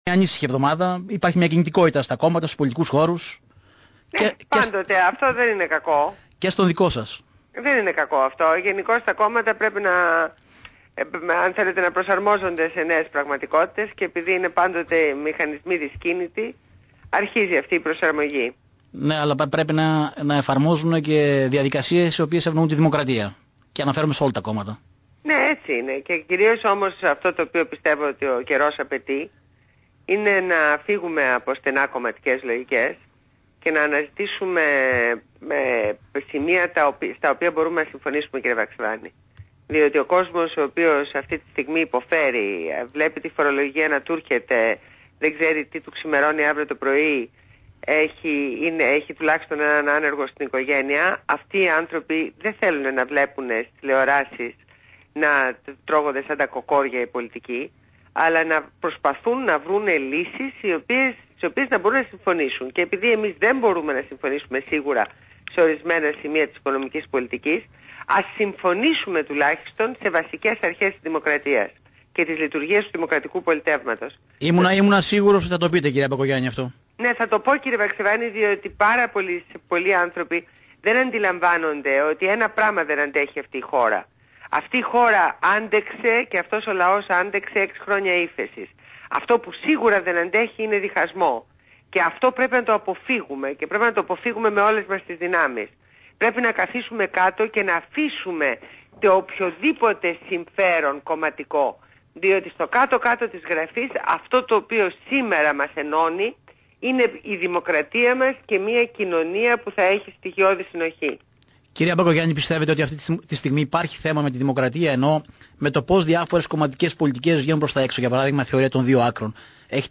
Συνέντευξη στο ραδιόφωνο, Στο κόκκινο στο δημοσιογράφο Κ. Βαξεβάνη.
Ακούστε τη ραδιοφωνική συνέντευξη της Ντόρας Μπακογιάννη στο δημοσιογράφο Κώστα Βαξεβάνη, Στο κόκκινο.